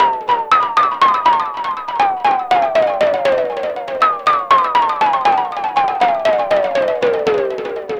Astro 4 Synth Fx.wav